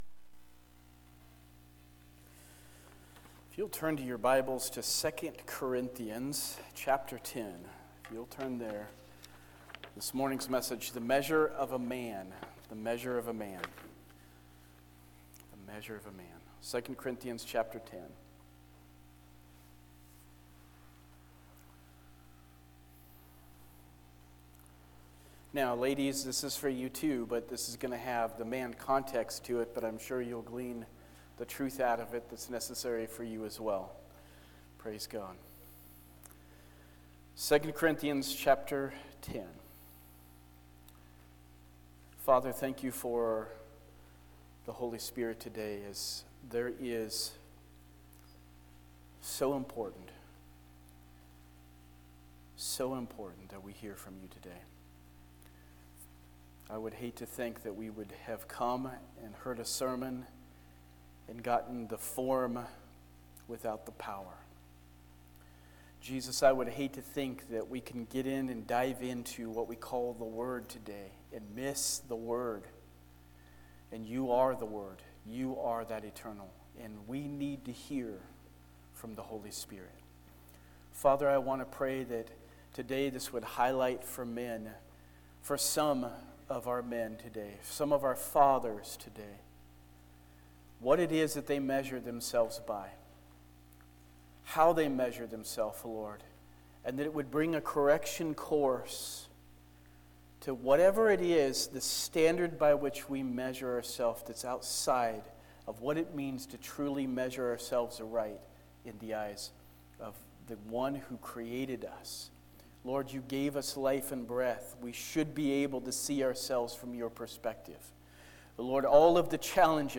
Sermons by Abundant Life Assembly